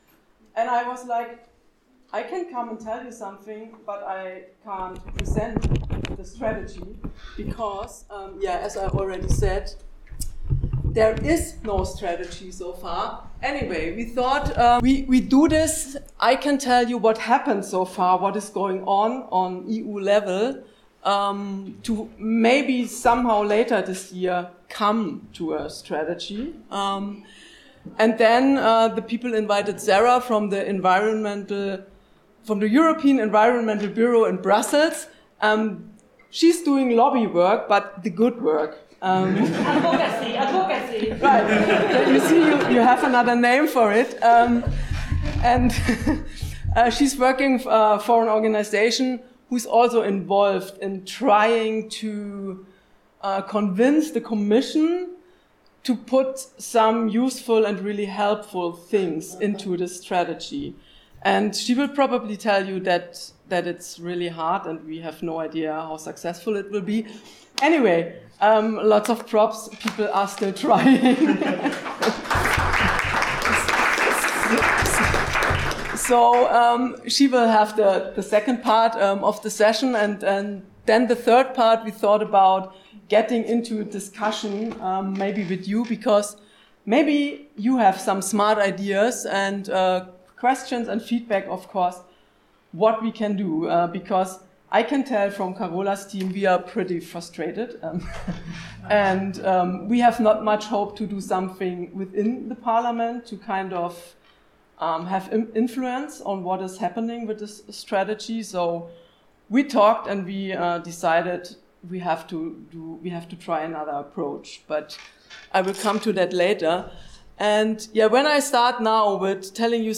Wasserkonferenz: Workshop – Die EU-Strategie für Wasserresilienz – radio nordpol